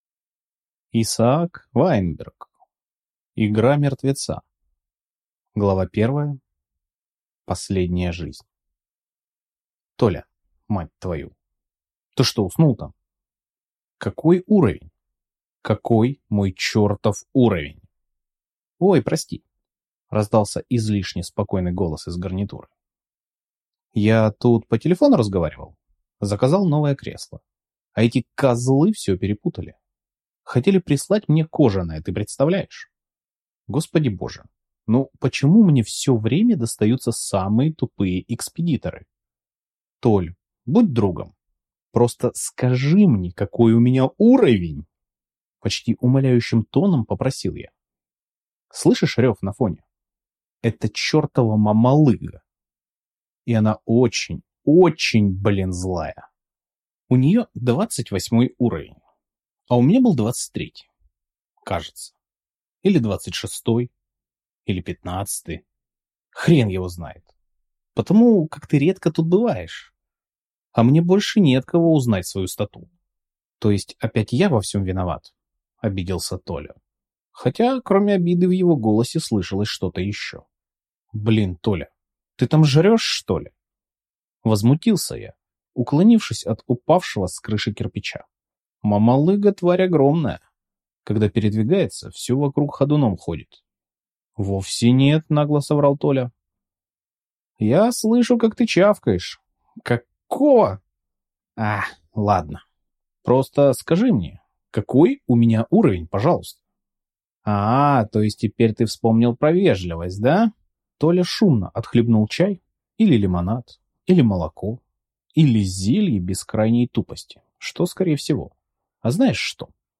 Аудиокнига Игра Мертвеца | Библиотека аудиокниг